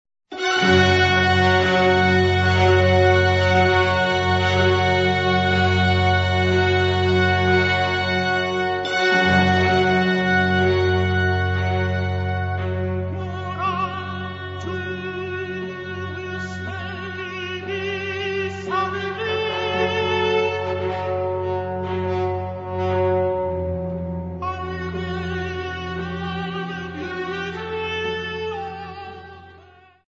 Counter tenor
Flute
Harp
Soprano sax
Strings